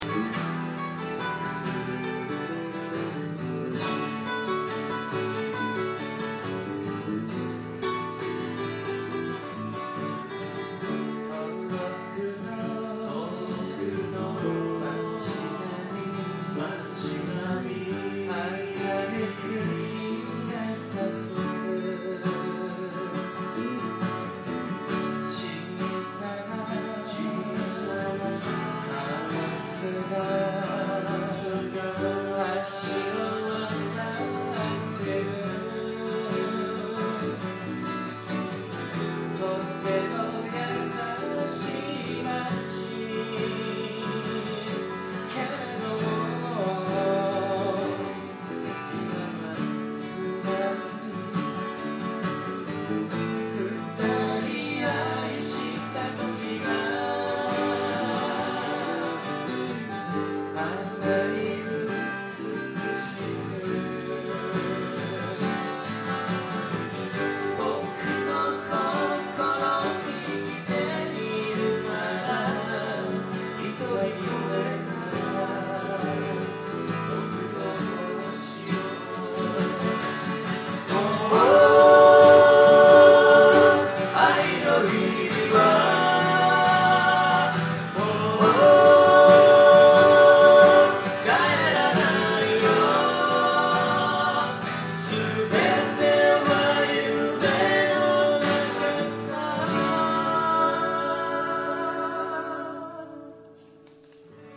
愛知県名古屋市　「アートピア」
今回の録音は多少レベルが低かったので、パソコンのボリュームを